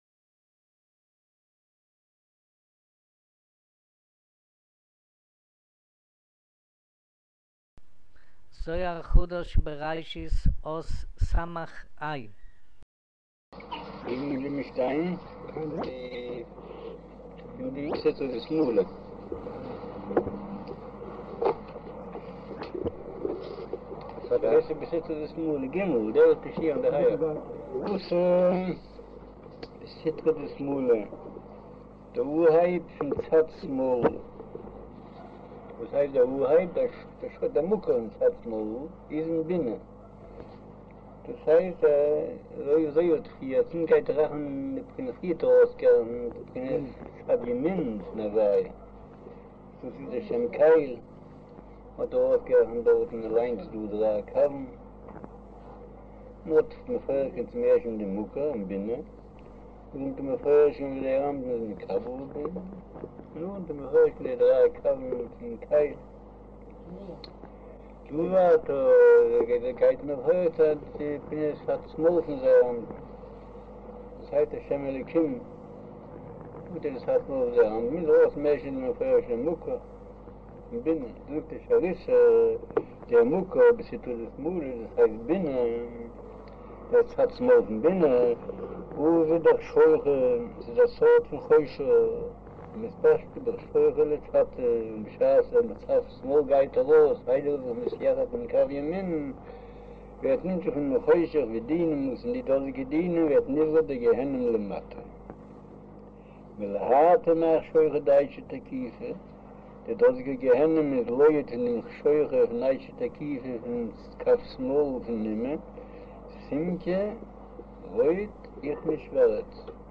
אודיו - שיעור מבעל הסולם זהר חדש בראשית אות סה' - סח'